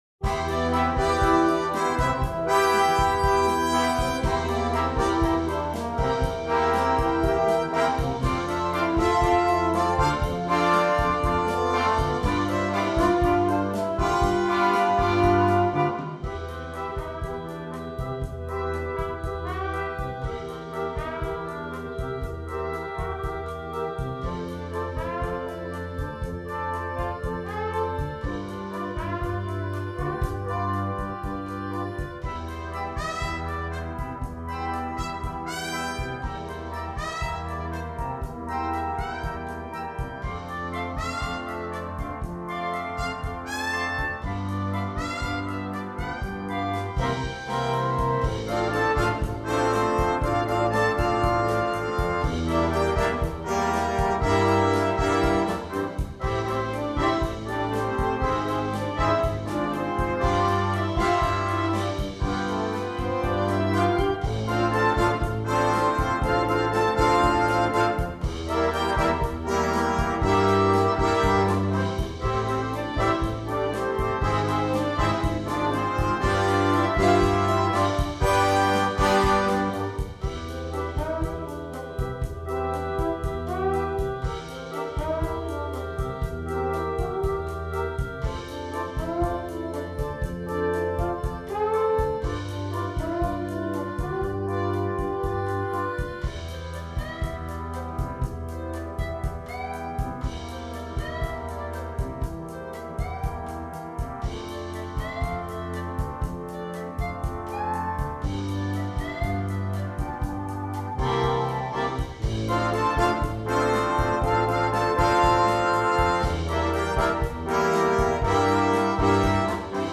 2. Blaskapelle
komplette Besetzung
ohne Soloinstrument
Unterhaltung